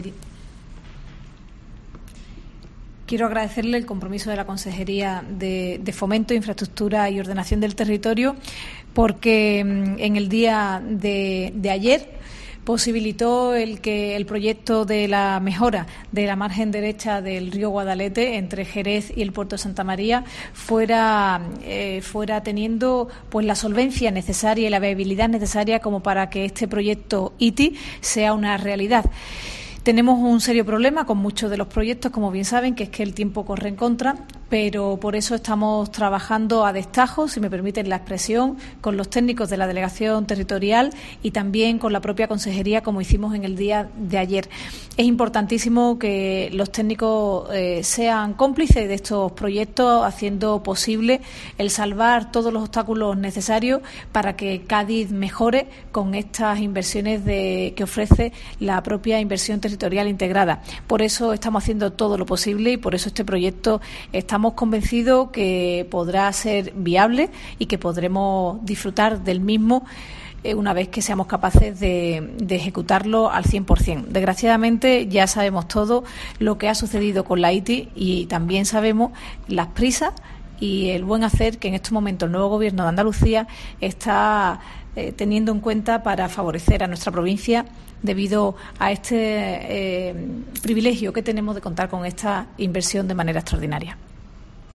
La delegada de la Junta Ana Mestre sobre el proyecto de recuperación del Guadalete